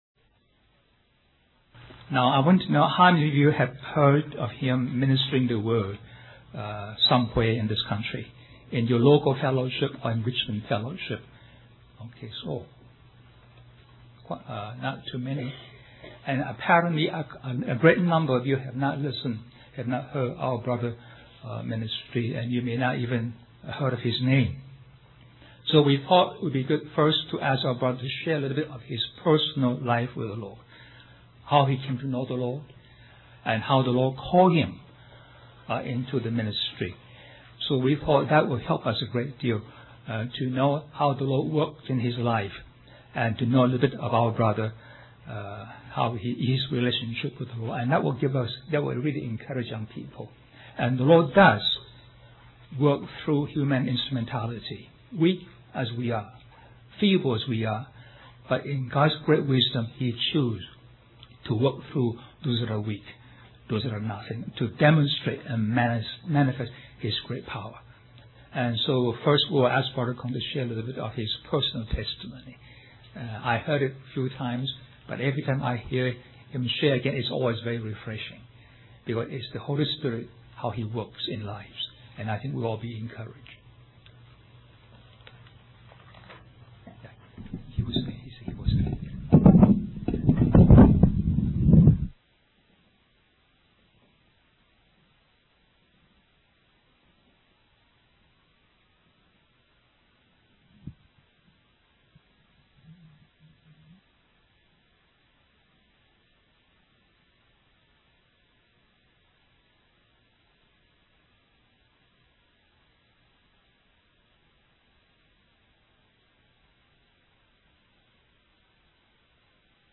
1996 Christian Youth Conference - Question and Answer